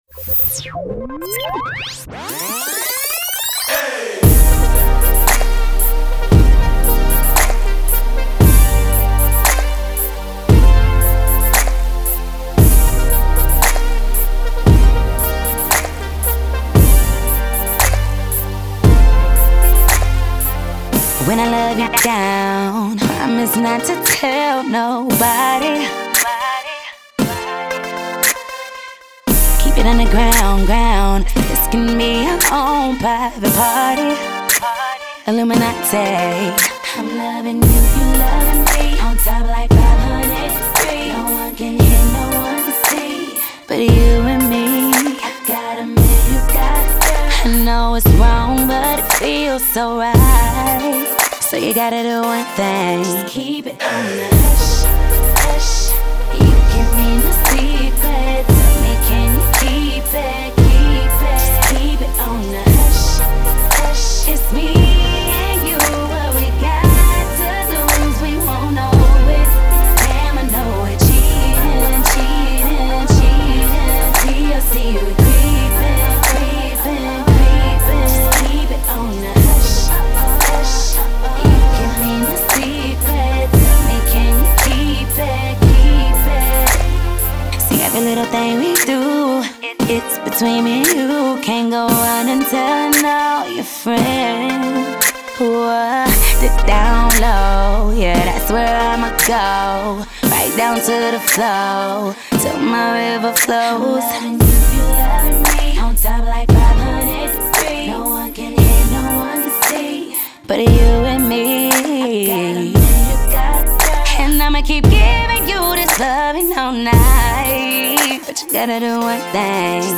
RnB
R&B Produced by